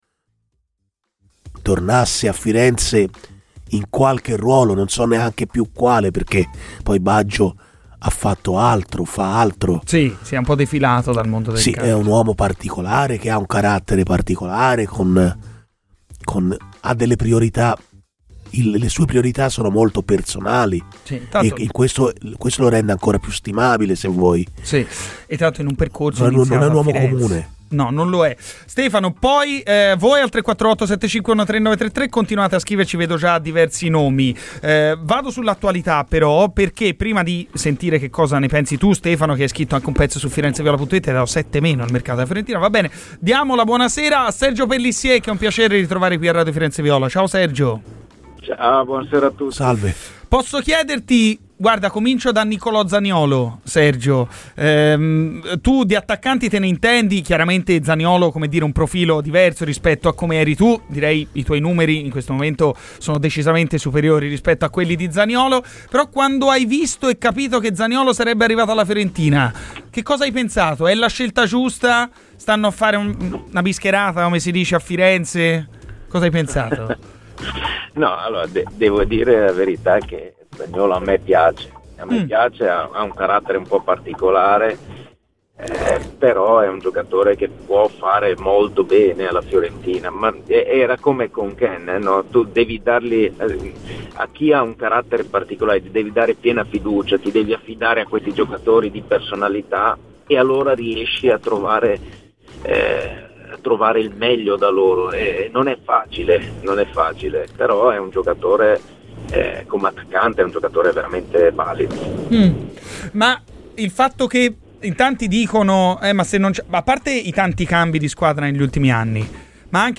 L'ex attaccante del Chievo Verona, oggi presidente della formazione veneta, Sergio Pellissier è intervenuto ai microfoni di Radio FirenzeViola nel corso della trasmissione "Garrisca al Vento".